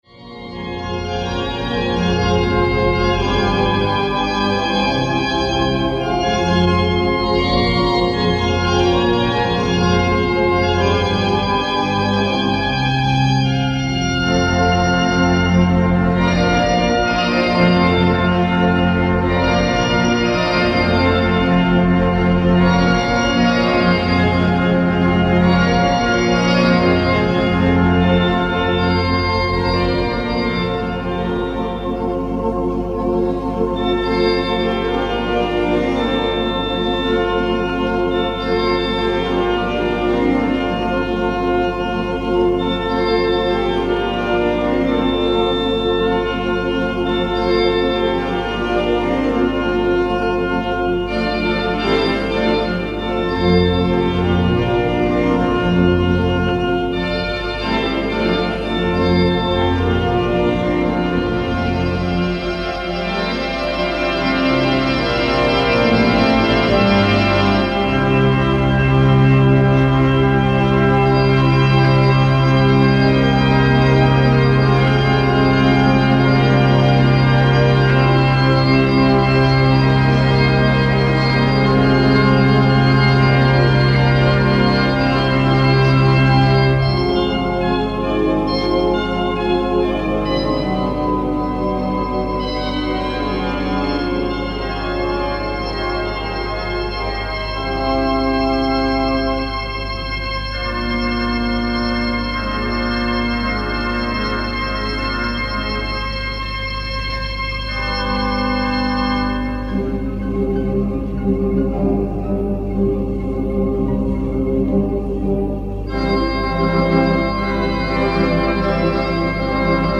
Rumba-Toccata  (Toccvata Meets Rumba)
Herbst-Bolero (Bolero In Autumn)